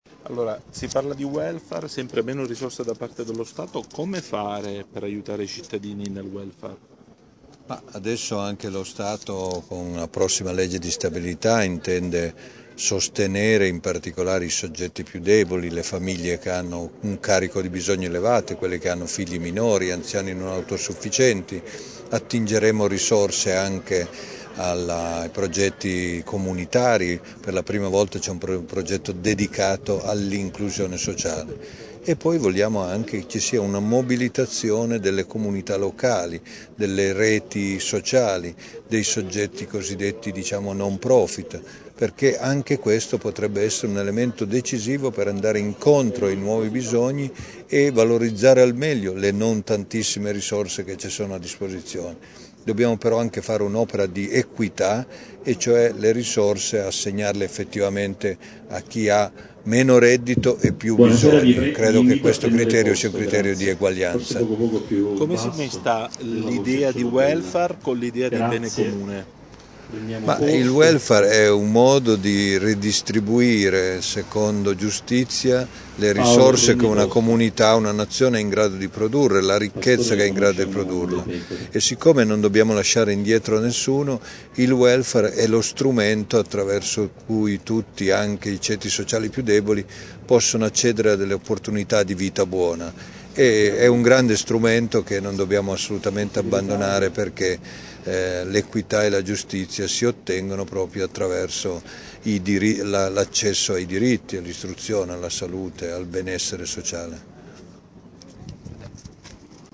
[ASCOLTA QUI L’INTERVISTA], già presidente delle Acli, ha evidenziato l’importanza del territorio in un’azione corale di interventi: «La povertà – ha detto il sottosegretario – oggi più di ieri è concentrata nelle famiglie dove il carico è più forte, con presenza di anziani.
viceministro_bobba.mp3